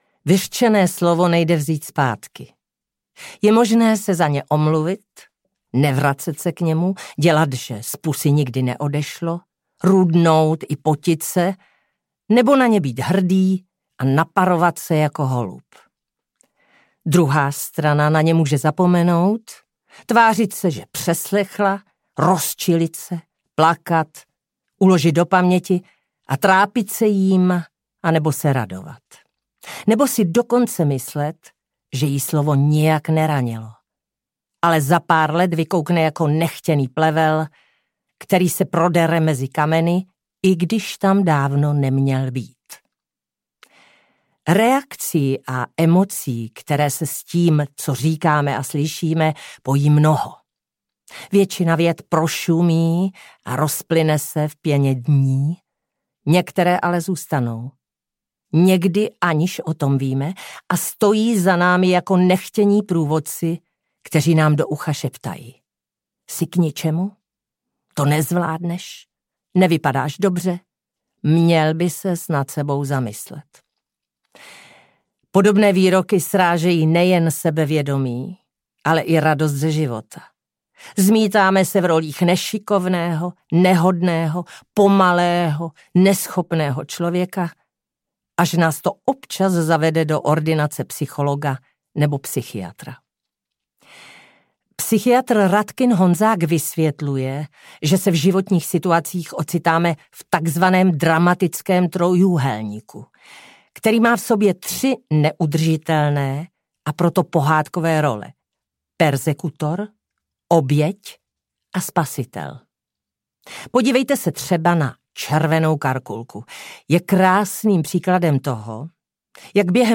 audiokniha